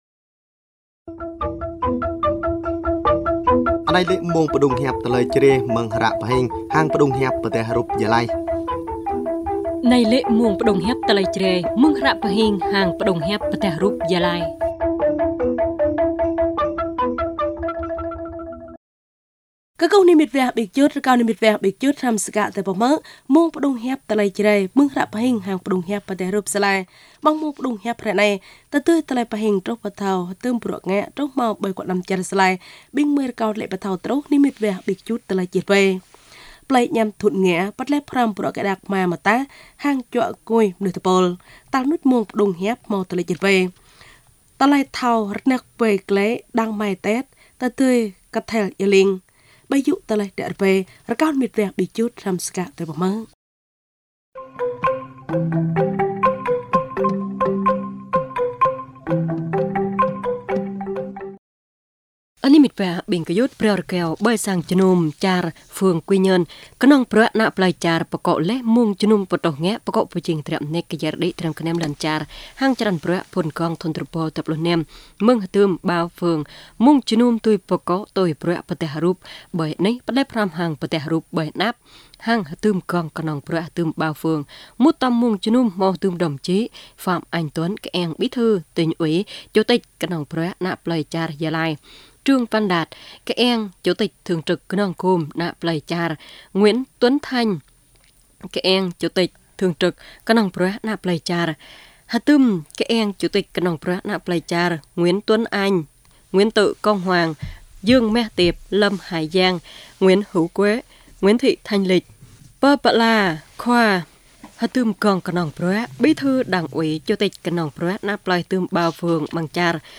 Thời sự PT tiếng Jrai